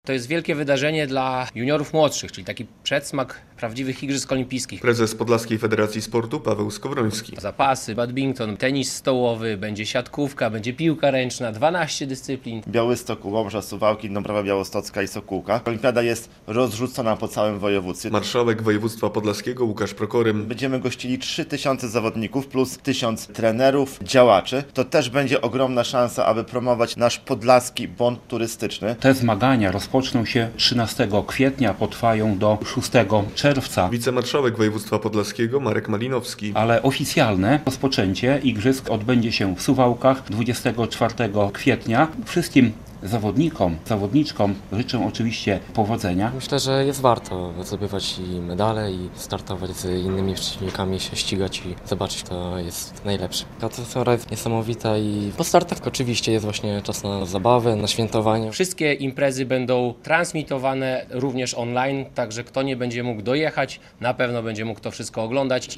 Ogólnopolska Olimpiada Młodzieży w Podlaskiem - relacja